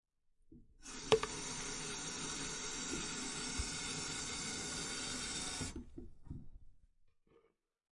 描述：填水